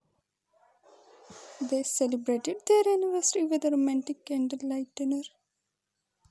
UniDataPro/speech-emotion-recognition at main
surprised.wav